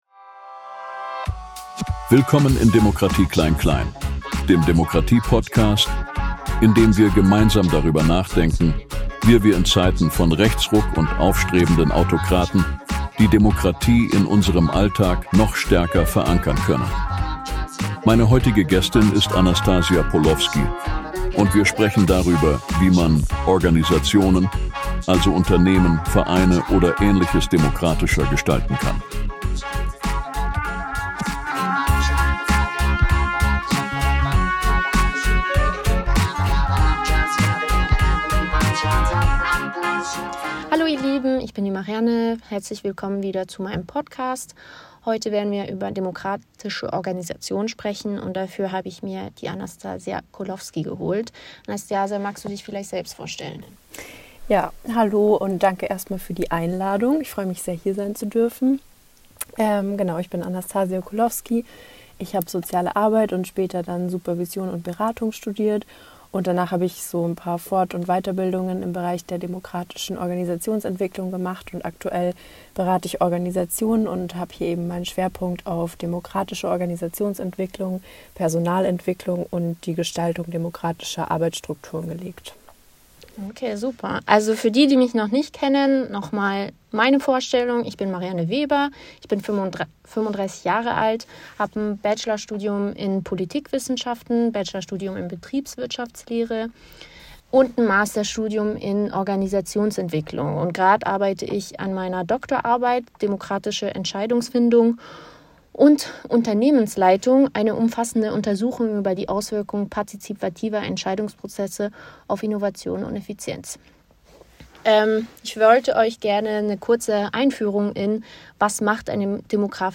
In unserem Podcast beleuchten wir, wie eine Organisation mithilfe eines Reflektionsworkshops auf ihrem Weg zur demokratischen Struktur begleitet werden kann. Wir haben uns dabei für das Medium Podcast entschieden, da wir es sinnvoll finden, den Transformationsprozess innerhalb eines Expert*innengespräches darzustel